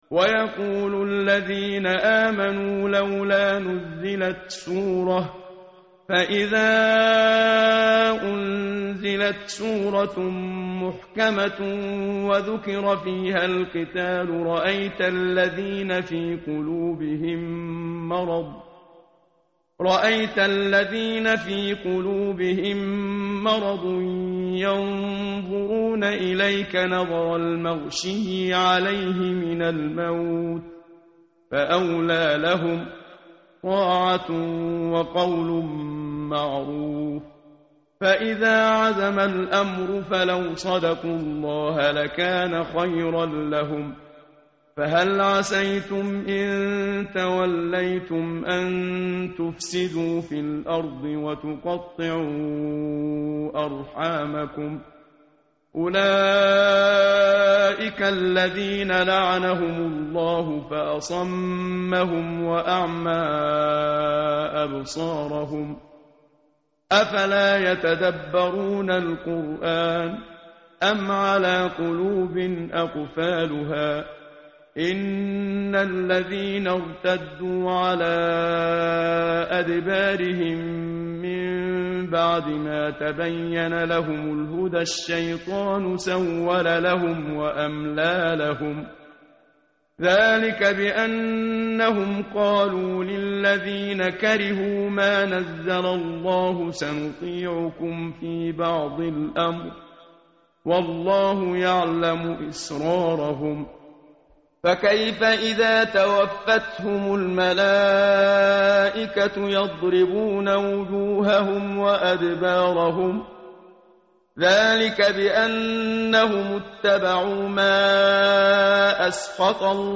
متن قرآن همراه باتلاوت قرآن و ترجمه
tartil_menshavi_page_509.mp3